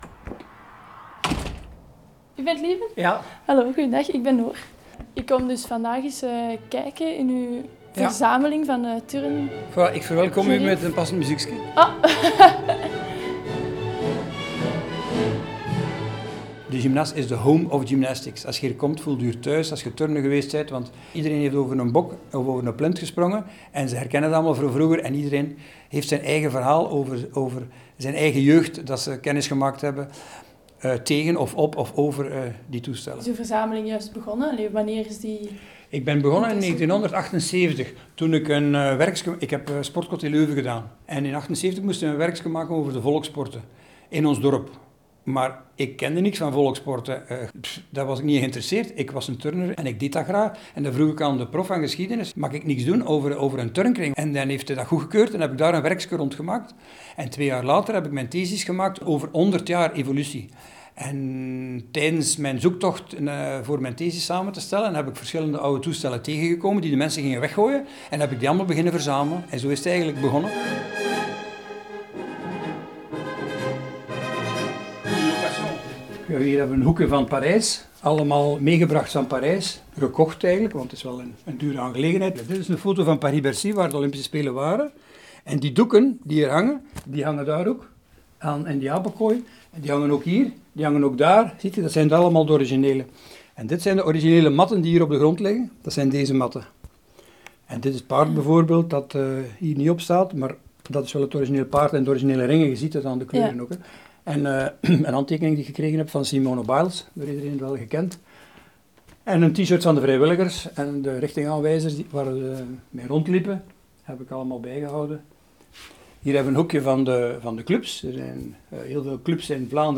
Reportage-De-Gymnas_mixdown.mp3